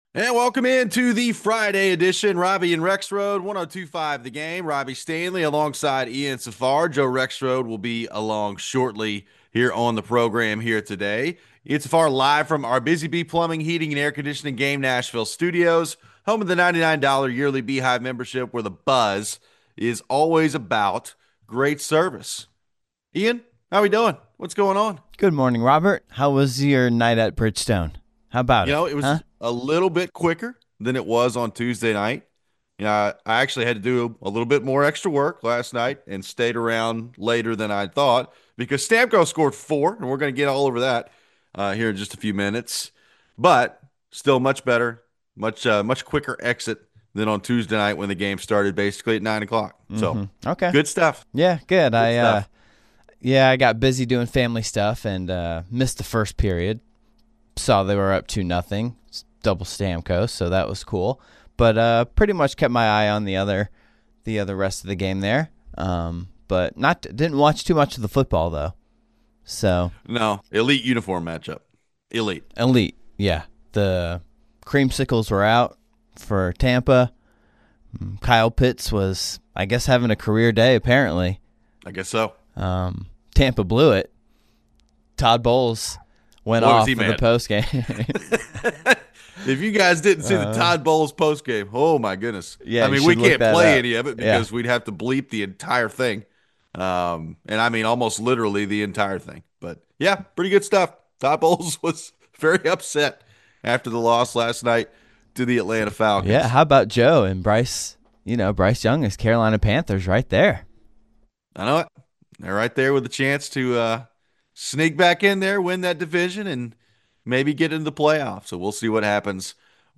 We take your phones. After missing the playoff, what does the future of Notre Dame's schedule look like?